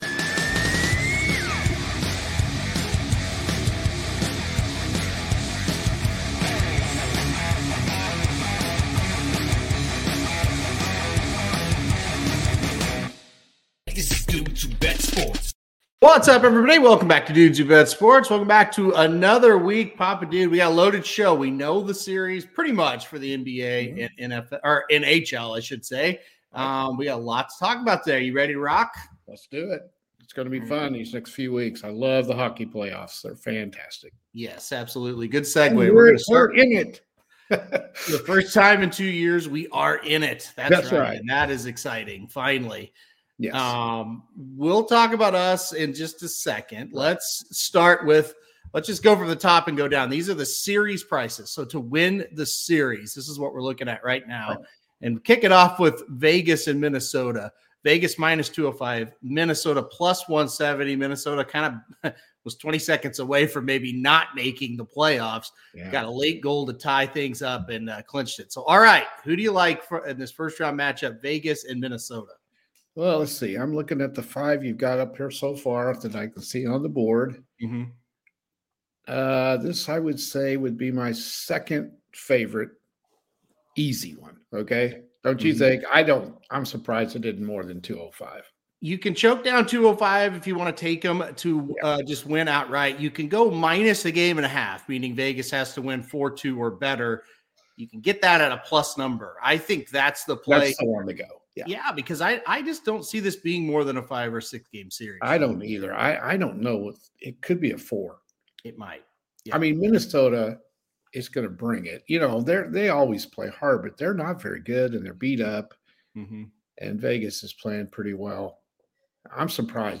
The duo will also answer questions sent in from the listeners.